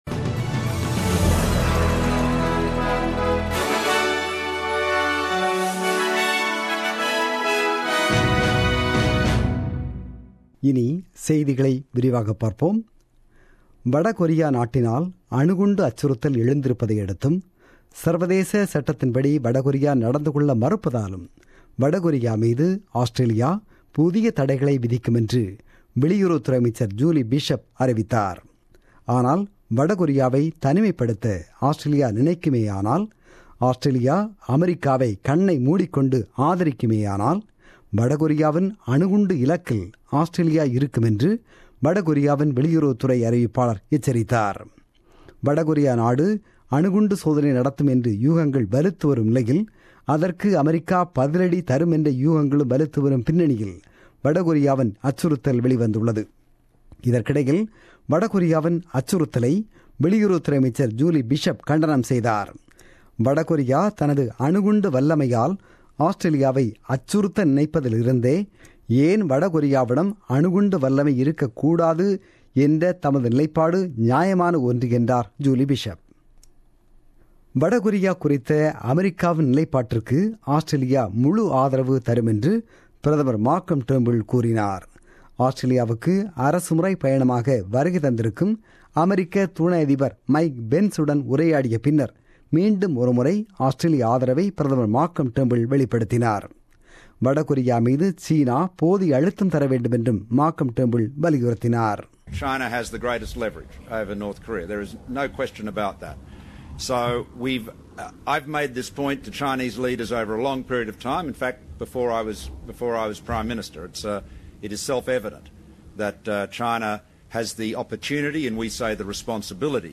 The news bulletin broadcasted on 23 April 2017 at 8pm.